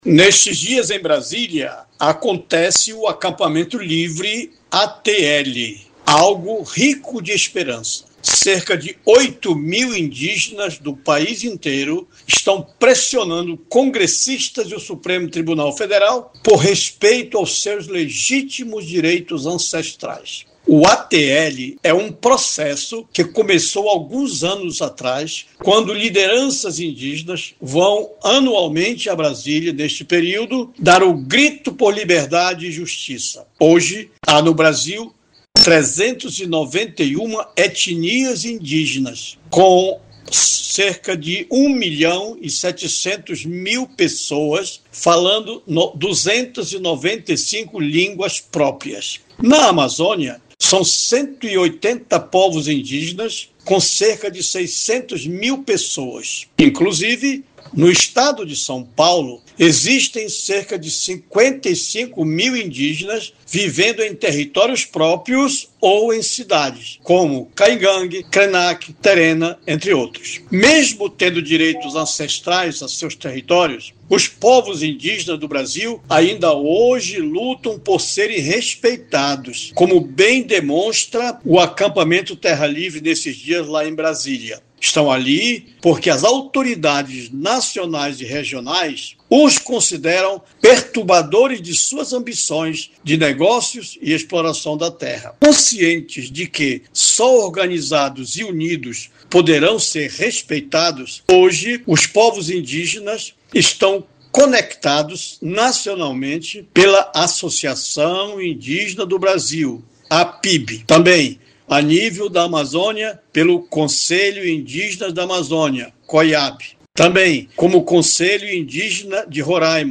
EDITORIAL-16.mp3